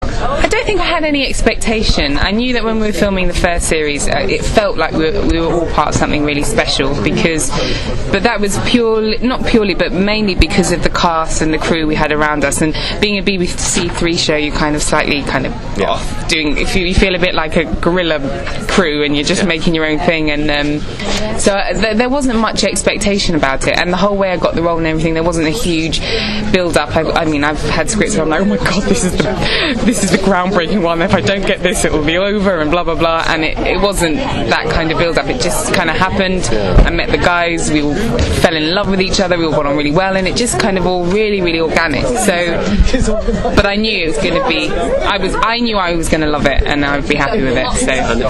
And here’s are a few short audio extracts from the interview with Lenora: